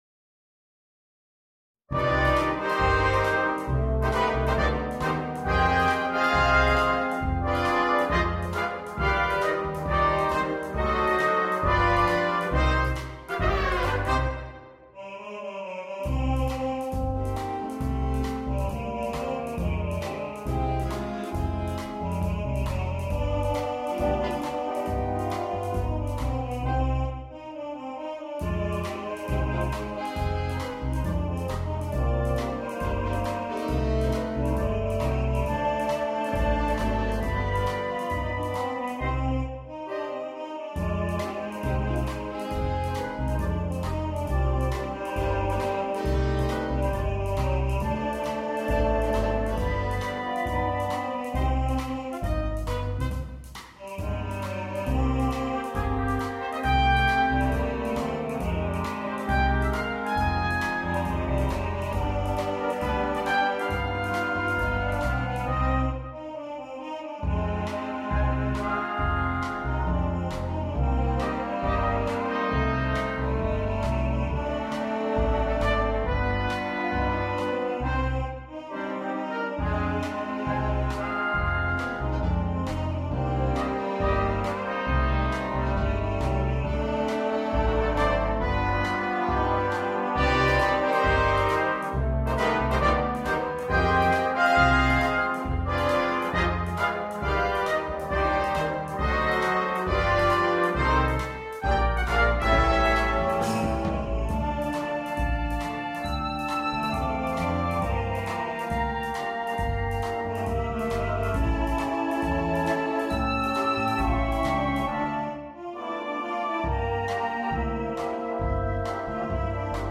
для духового оркестра